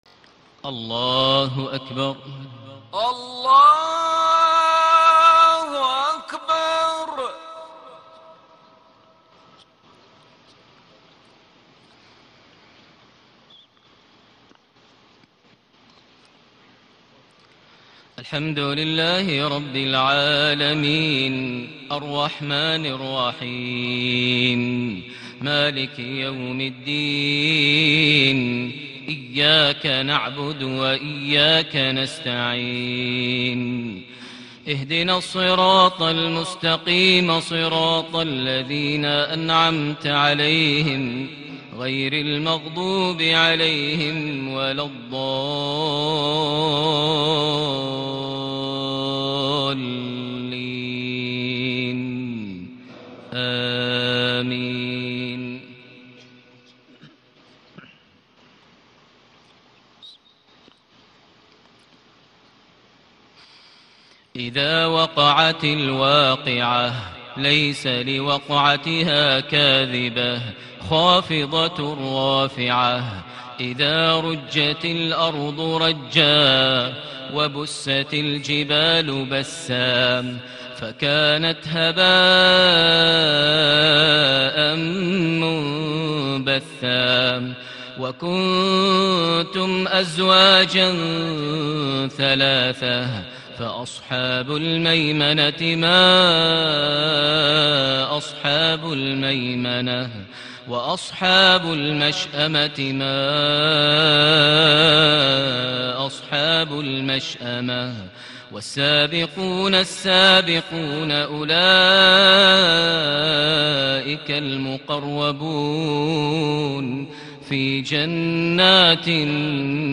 صلاة العشاء ٢٧ صفر ١٤٣٨هـ سورة الواقعة ١-٥٦ > 1438 هـ > الفروض - تلاوات ماهر المعيقلي